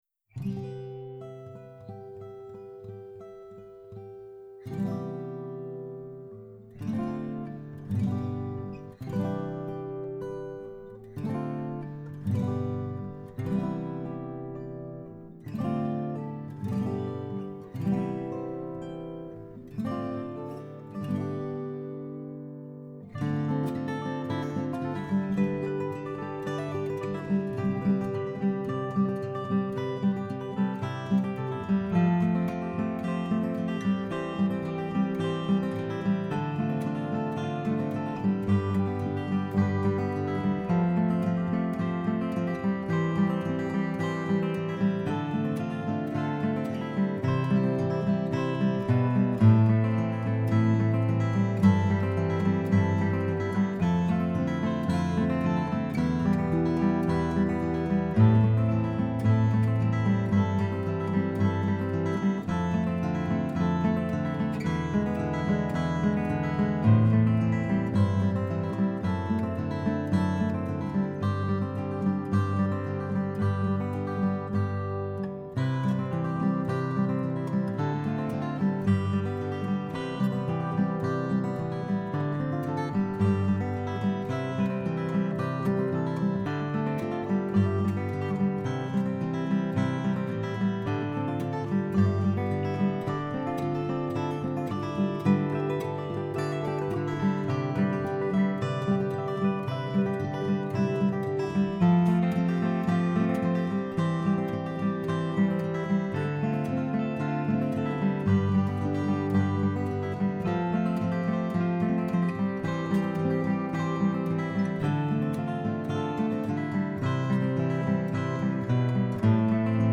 Guitar Mix